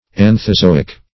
Anthozoic \An"tho*zo"ic\, a. Of or pertaining to the Anthozoa.